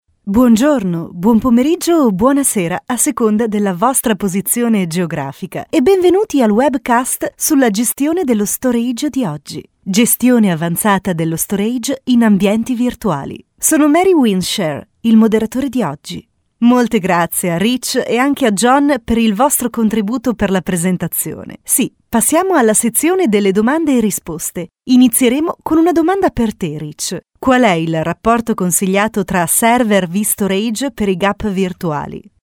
Voce versatile per voiceovers, radio show, pubblicità e messaggi di segreteria telefonica
Sprechprobe: eLearning (Muttersprache):
Italian Voice Over Talent & Radio Personality for narrations, advertising, documentaries and phone messages